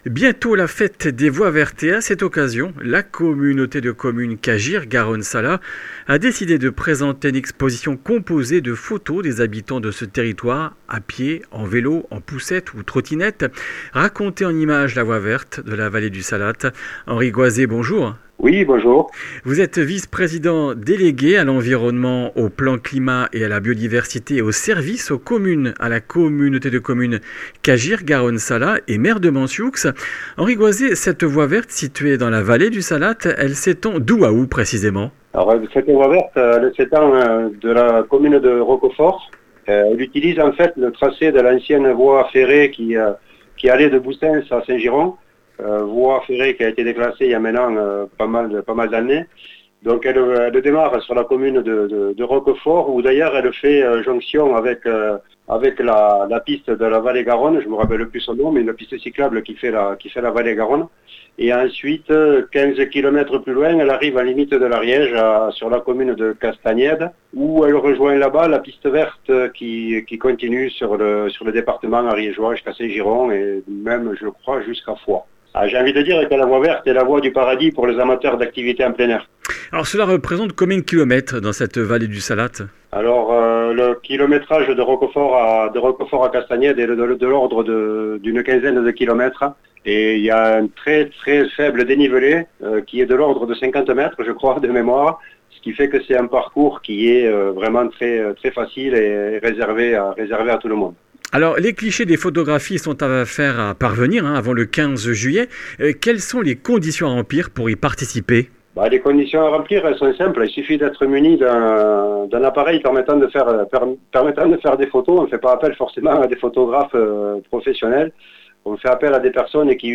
Comminges Interviews du 10 juin